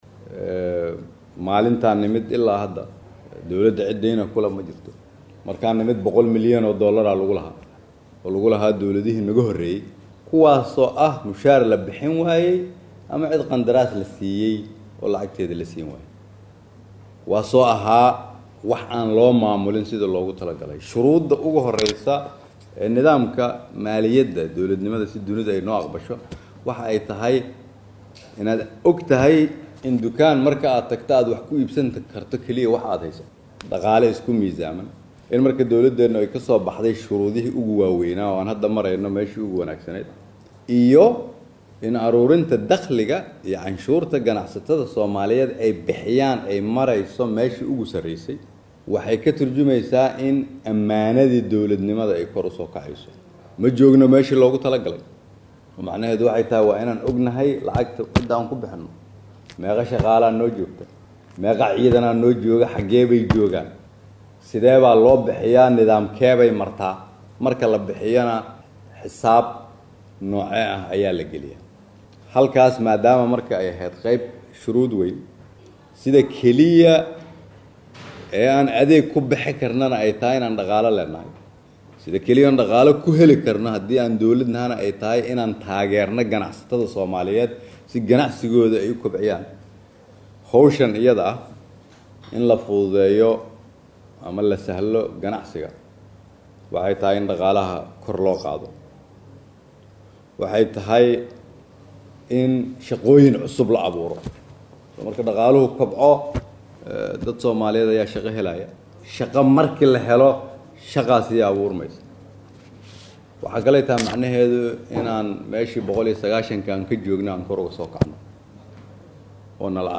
Hadalkaan ayuu ka jeediyay kadib kulan uu la qaatay ganacsatada Soomaaliyeed oo uu kala hadlayay sidii loo horumarin lahaa dhaqaalaha dalka iyo bixinta canshuuraha ay bixiyaan ganacsatada Soomaaliyeed.
Hoos ka dhageyso codka Ra’iisul wasaare Kheyre.